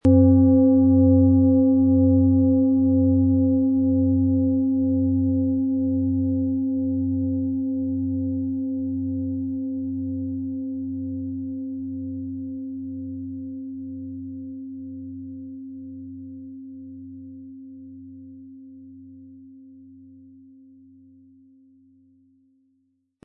• Tiefster Ton: Tageston
Mit einem sanften Anspiel "zaubern" Sie aus der Biorhythmus Körper mit dem beigelegten Klöppel harmonische Töne.
PlanetentöneBiorythmus Körper & Tageston
MaterialBronze